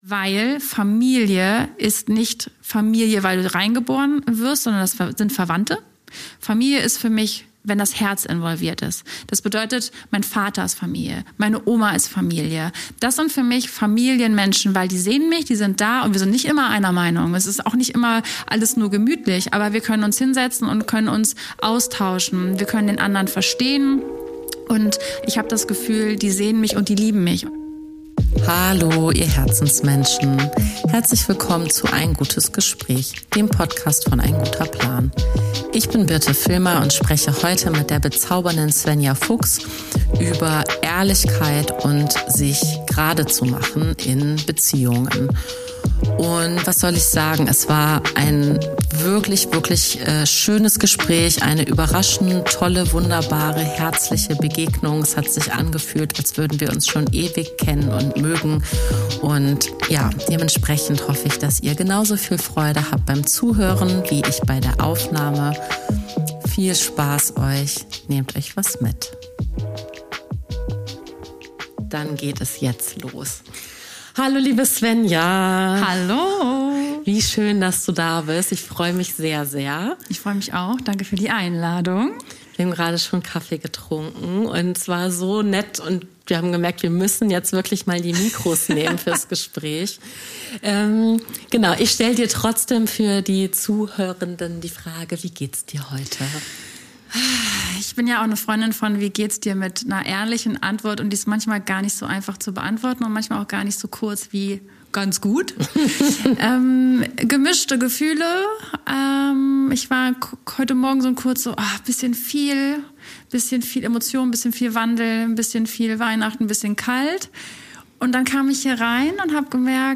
Ein stärkendes Gespräch darüber, mutiger zu sein und sich selbst ernst zu nehmen.